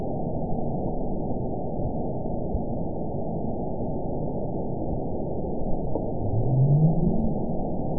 event 913795 date 04/20/22 time 20:20:55 GMT (3 years, 1 month ago) score 9.15 location TSS-AB01 detected by nrw target species NRW annotations +NRW Spectrogram: Frequency (kHz) vs. Time (s) audio not available .wav